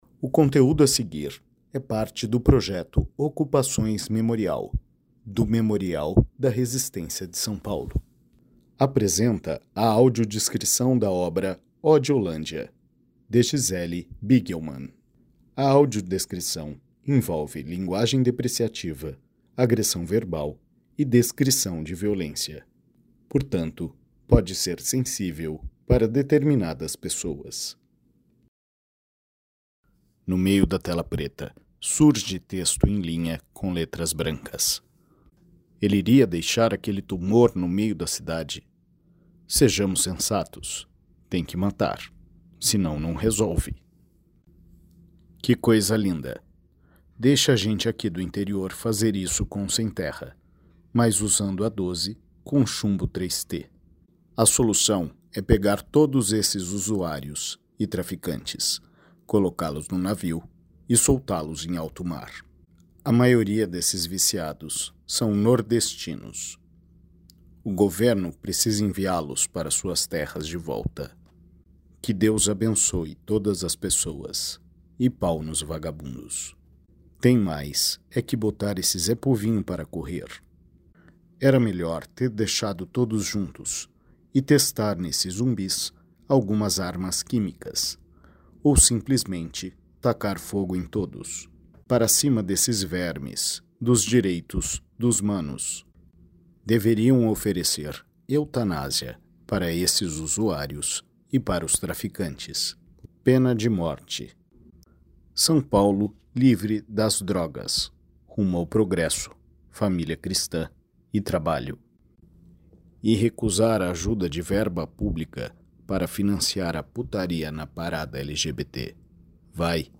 Audiodescricao-Odiolandia.mp3